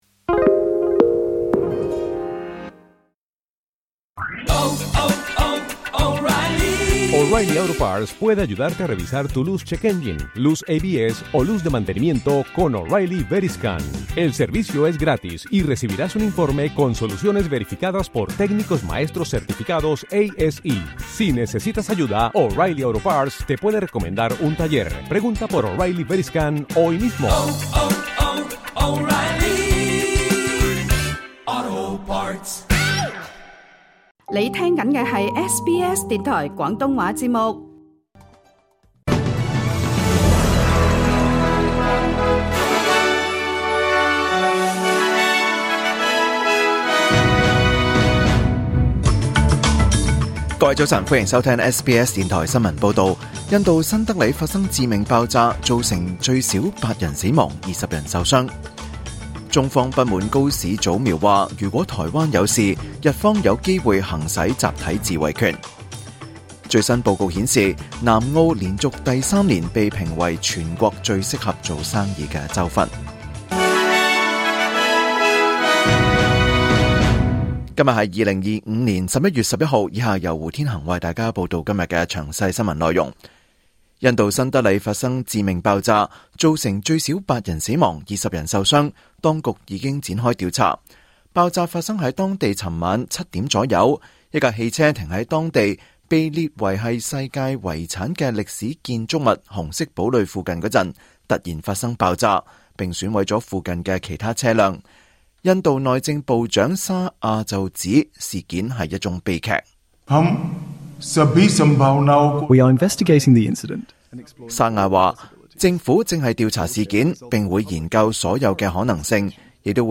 2025年11月11日SBS廣東話節目九點半新聞報道。